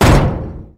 gibmetal5.wav